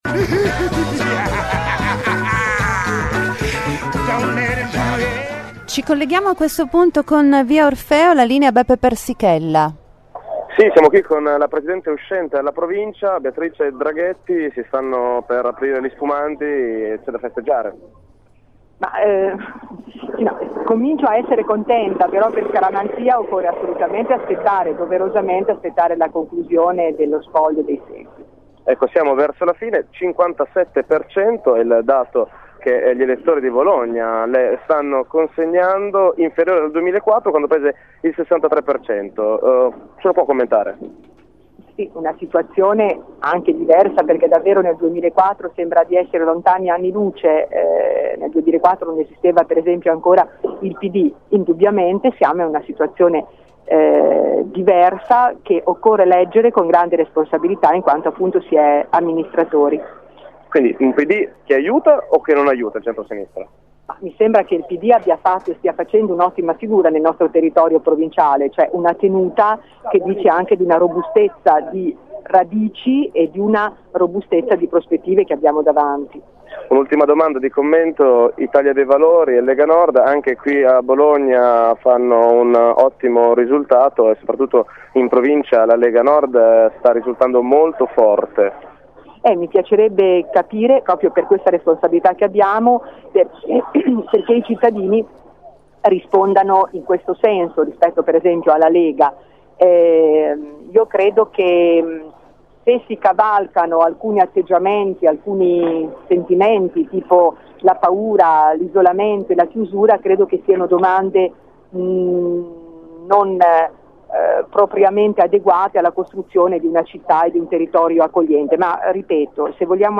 La neopresidente, riconfermata alla guida di palazzo Malvezzi esprime la sua sodisfazione ai nostri microfoni